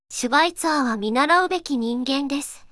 voicevox-voice-corpus
voicevox-voice-corpus / ita-corpus /四国めたん_セクシー /EMOTION100_002.wav